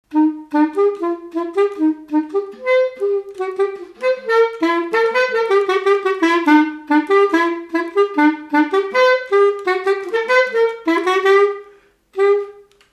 instrumental
circonstance : fiançaille, noce
Pièce musicale inédite